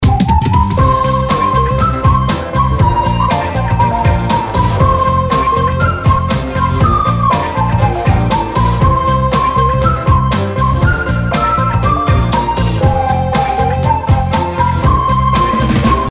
Просто свист)))
Хотя битрейт 256, качество неважное.